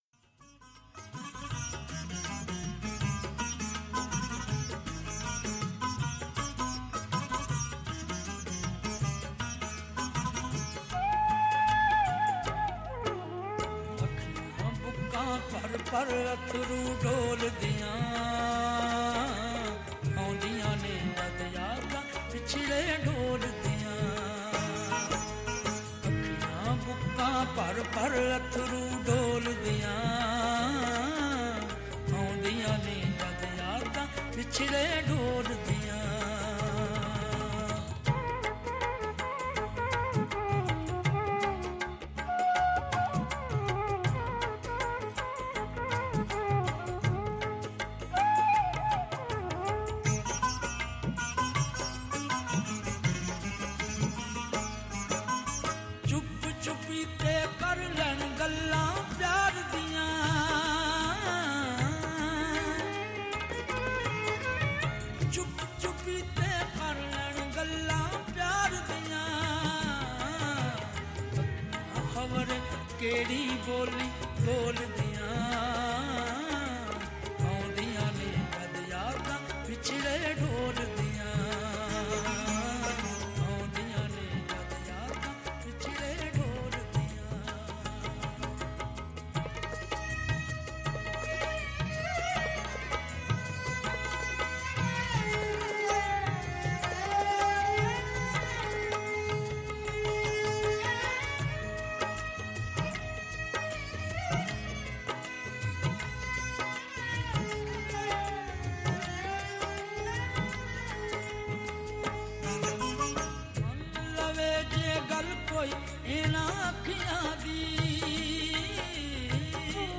powerful and magical voice